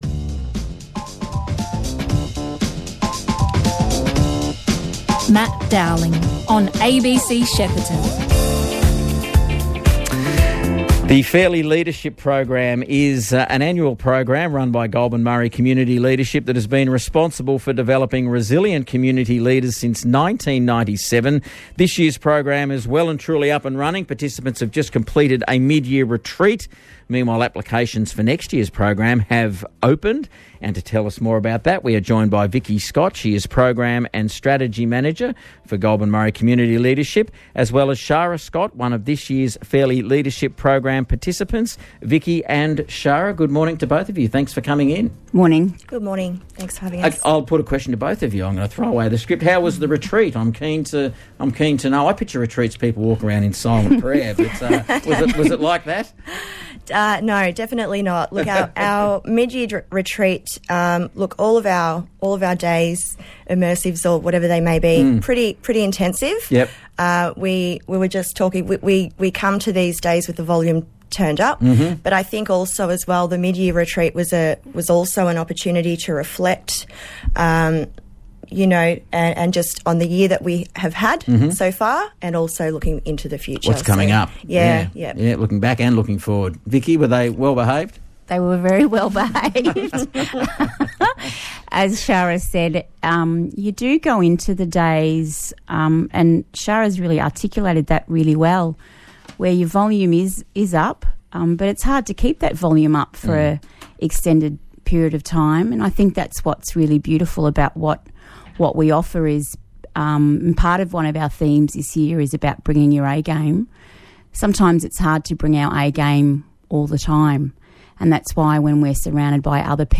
INTERVIEW 18.07.22